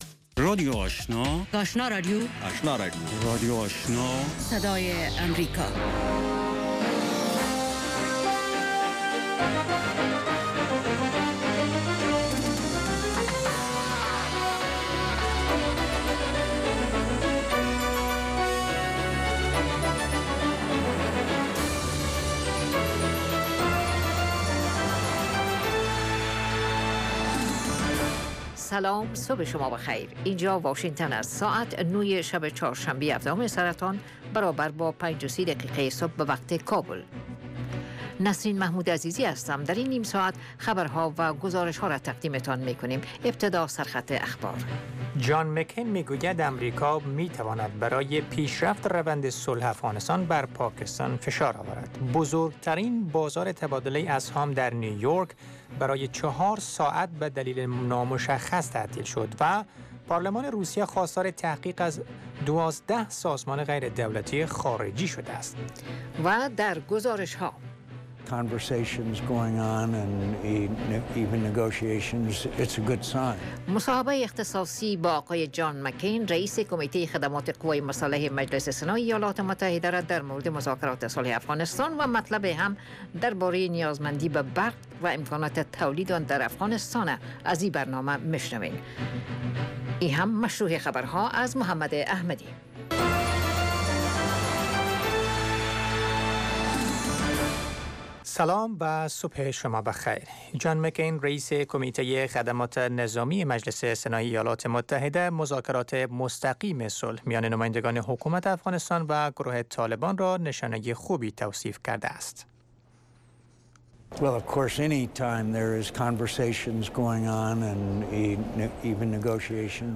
اولین برنامه خبری صبح